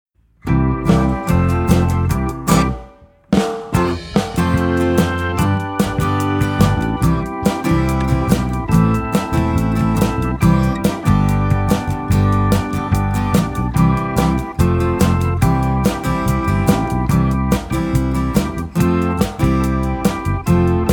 Listen to a sample of this instrumental song
Downloadable Instrumental Track